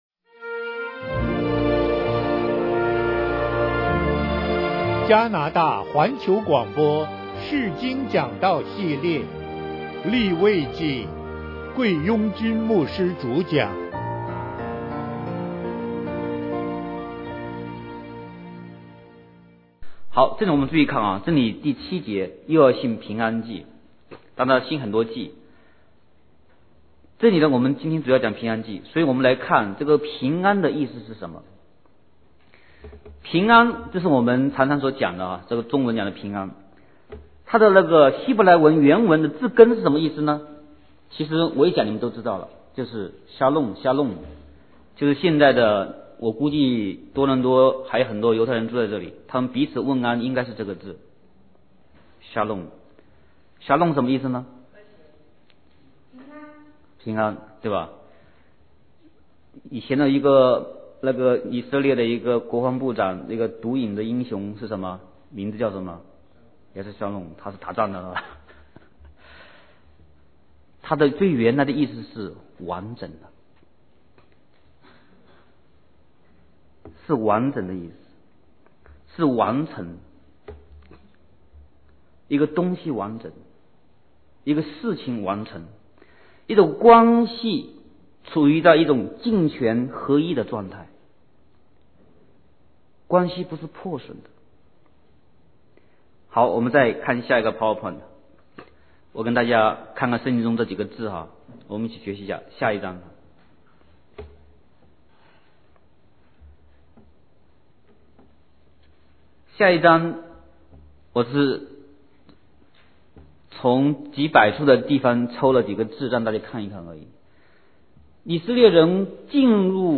釋經講道系列 – 利未記 – 加拿大環球廣播